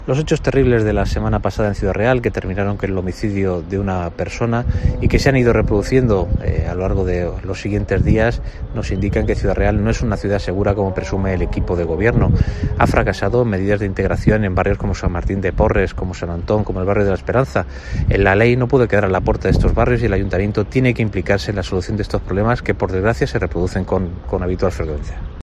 Paco Cañizares, portavoz grupo PP Ayuntamiento Ciudad Real